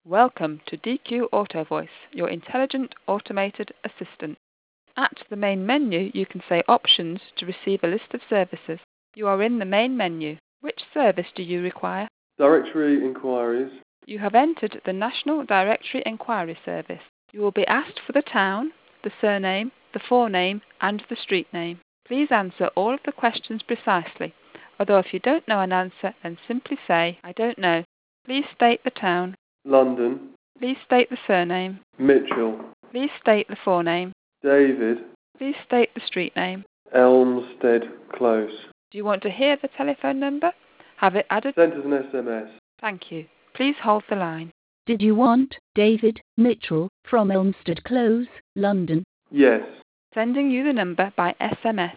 The best way to see why we say DQ Auto Voice is the leading voice-enabled directory solution available is to try our working demos and new product audio samples.